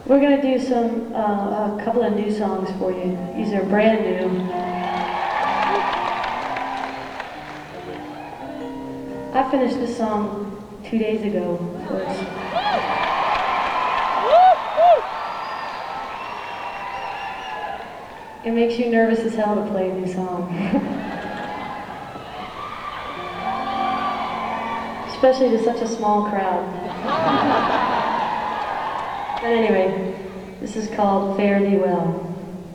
(acoustic duo)
11. talking with the crowd (0:32)